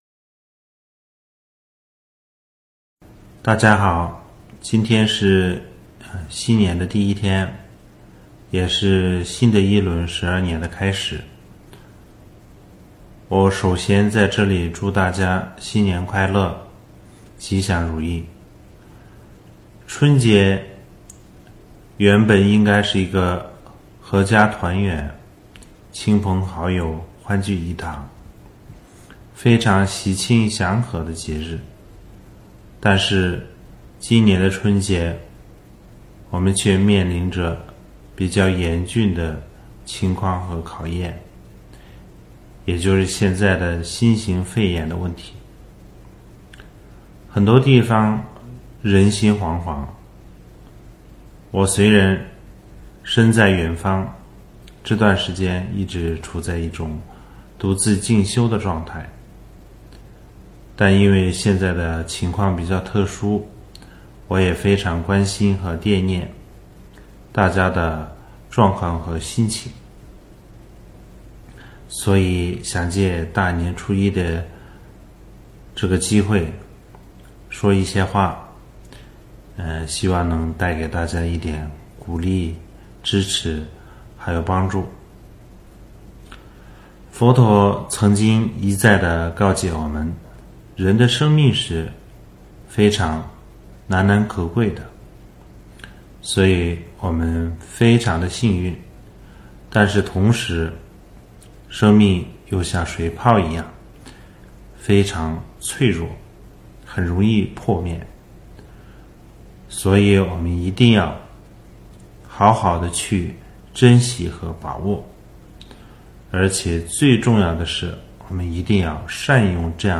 2020年新年第一天中文開示（音頻）｜第十七世大寶法王噶瑪巴鄔金欽列多傑